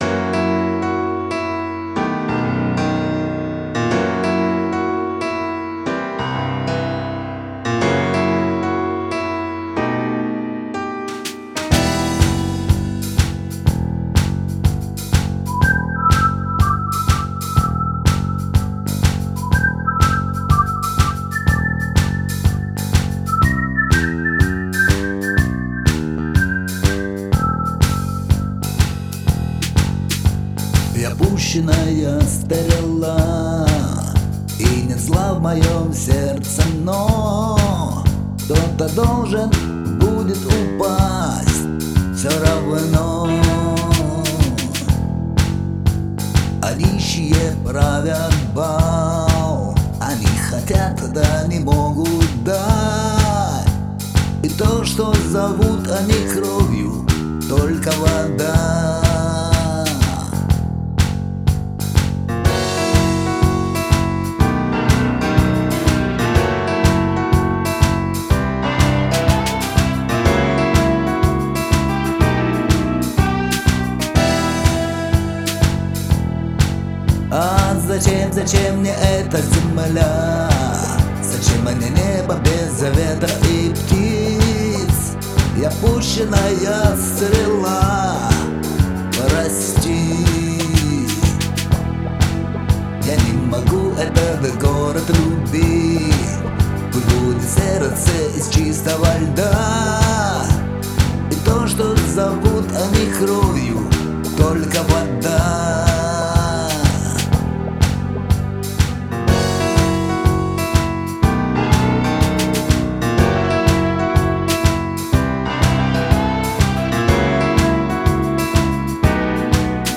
минусовка драйвовая получилась